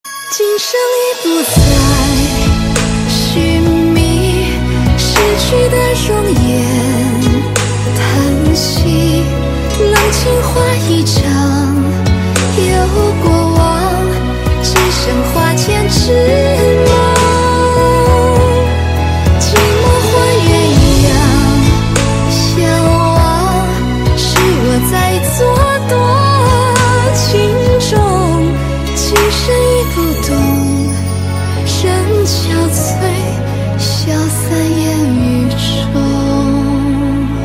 Thể loại nhạc chuông: Nhạc trung hoa